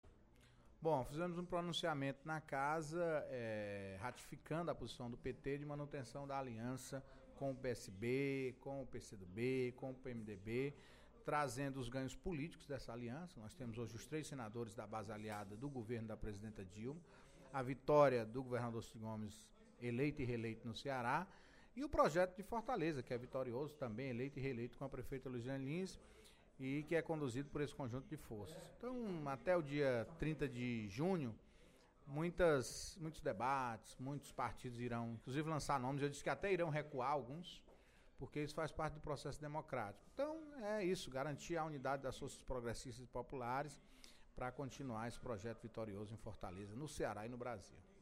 O líder do Governo a Casa, deputado Antonio Carlos (PT), disse, na manhã desta quinta-feira (15/03) em Plenário, que o fortalecimento da agricultura familiar foi o tema principal do discurso da presidente Dilma Rousseff, na posse do novo ministro do Desenvolvimento Agrário, Pepe Vargas.